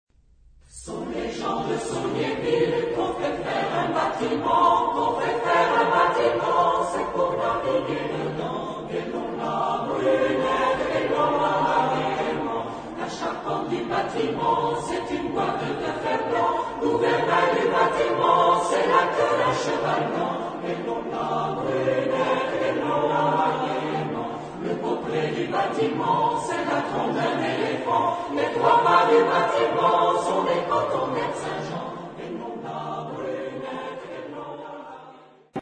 Género/Estilo/Forma: Folklore ; Profano ; Canción marinera
Tipo de formación coral: SATB  (4 voces Coro mixto )
Tonalidad : si bemol mayor